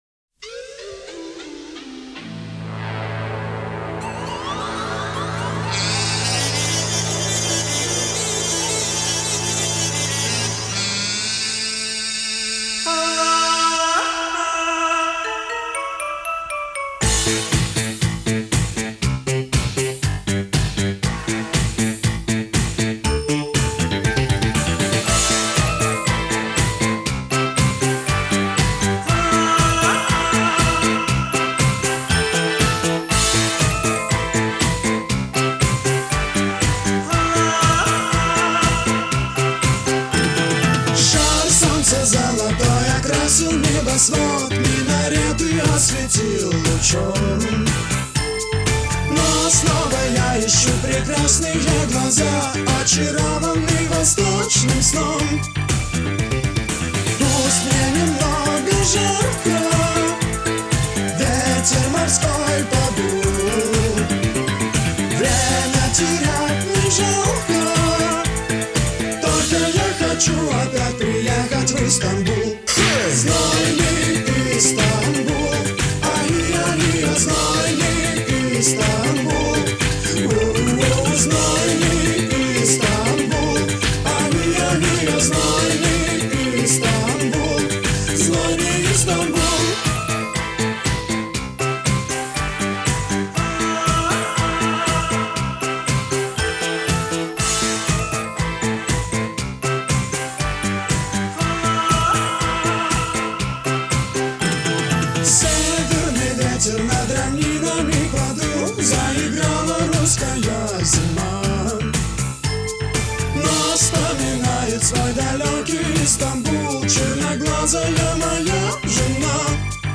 Музыкальный жанр: поп, ретро (диско 80-х)